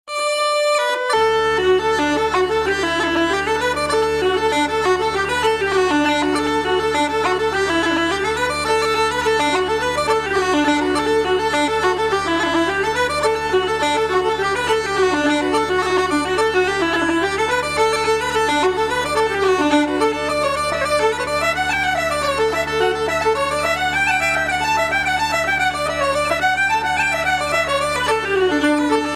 Fiddle
Guitar